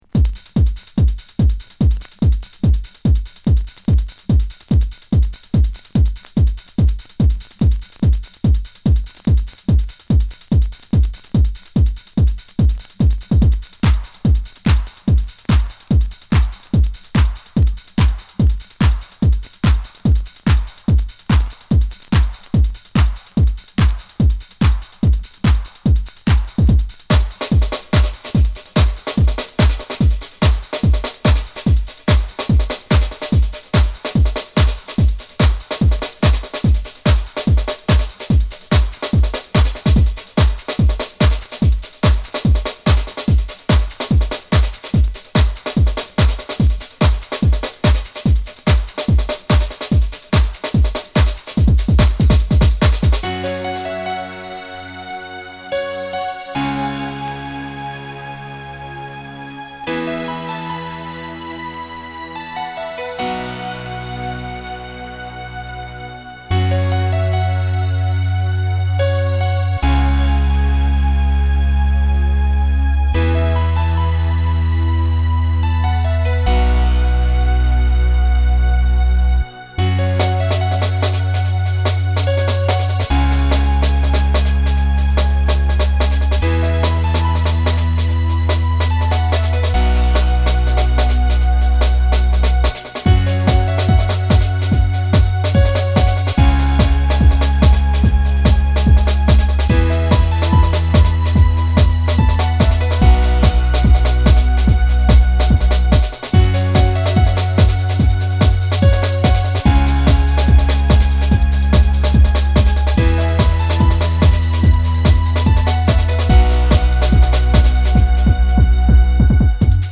レイヴテクノ・クラシック